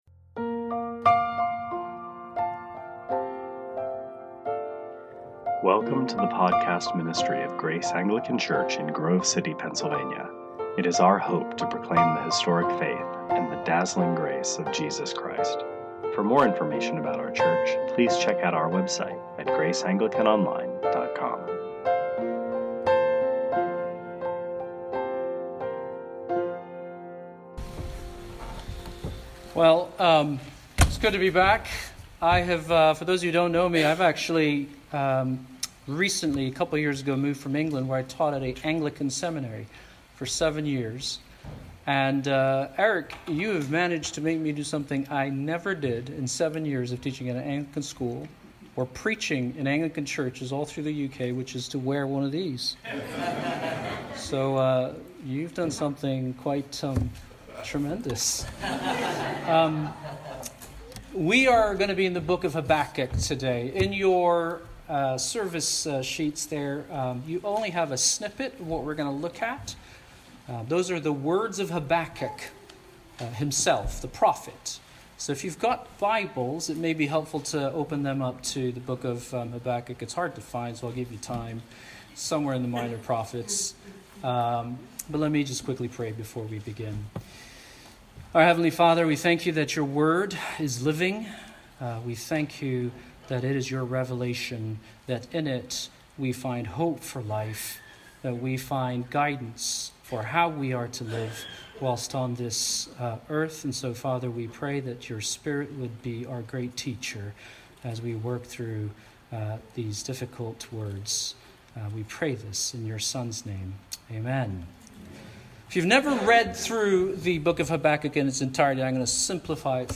2018 Sermons